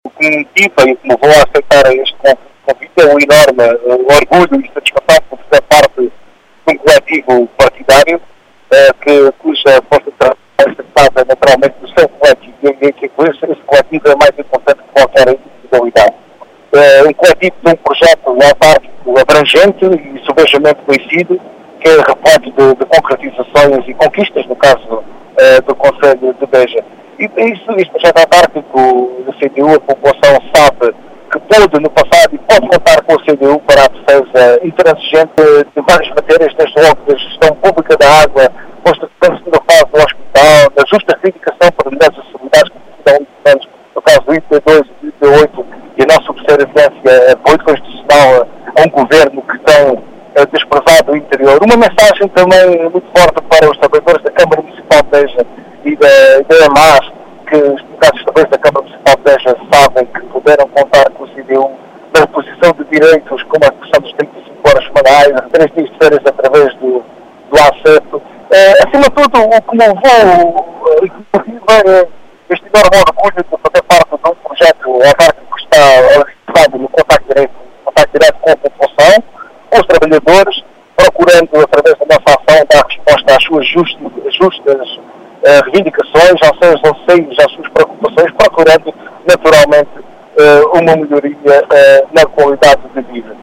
Em declarações à Rádio Vidigueira, Vitor Picado, diz ser com “orgulho” que aceitou este convite para liderar esta candidatura, e deixa as razões que justificam esta candidatura.